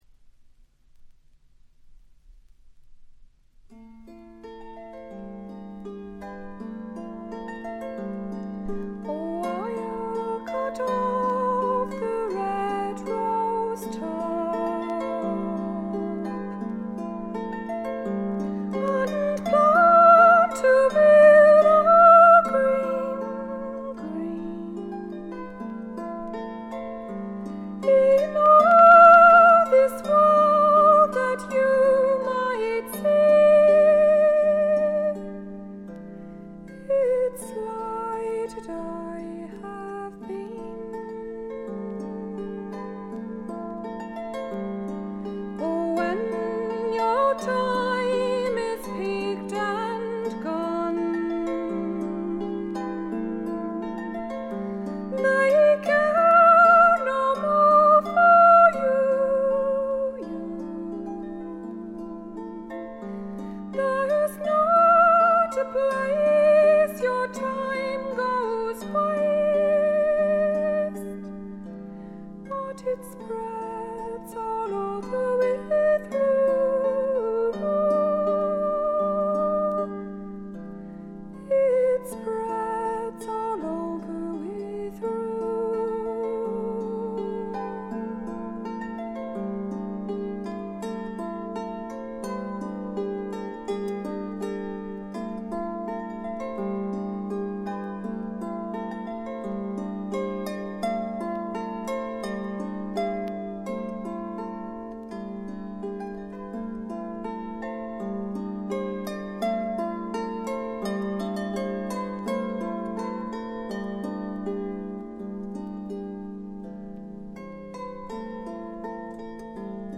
トラディショナル・ソングとトラッド風味の自作曲を、この上なく美しく演奏しています。
試聴曲は現品からの取り込み音源です。
vocal, harp, banjo
viola.